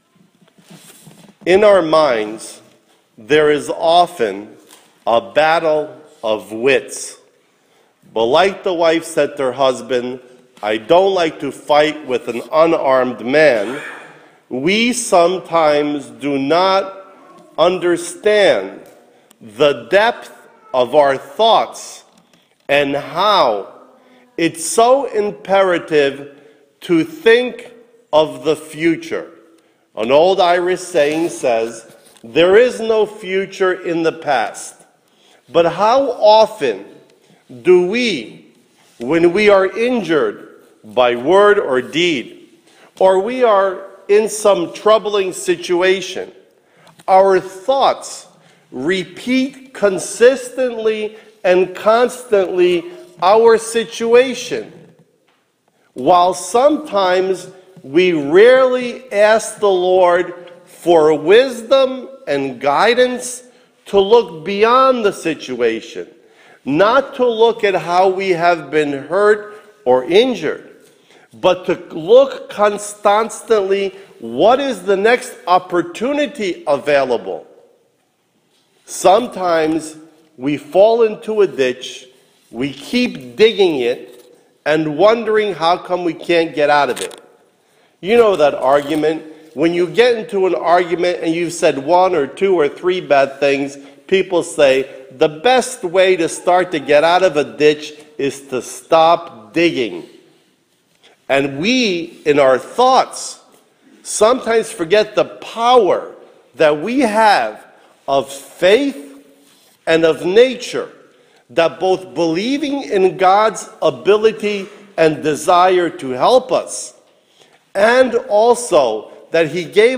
Audio Archive of Weekly Sermons -2016 / Аудіо архів щотижневих проповідей - 2016